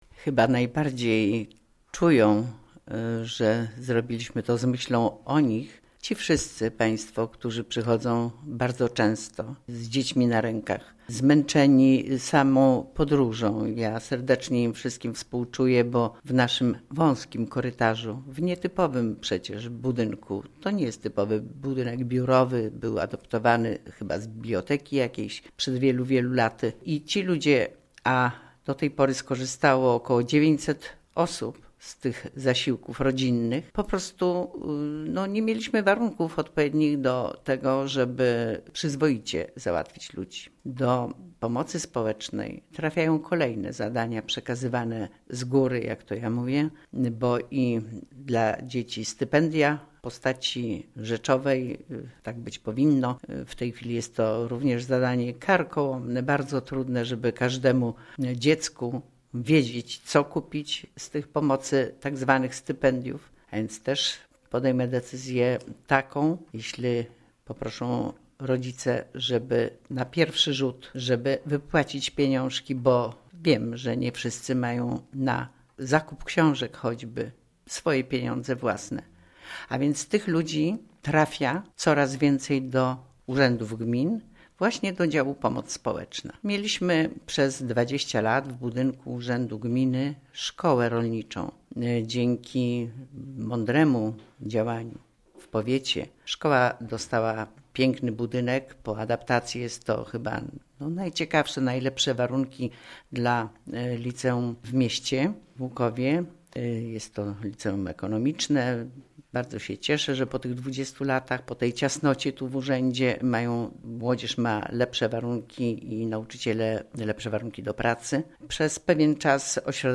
W�jt Gminy �uk�w Kazimiera Go�awska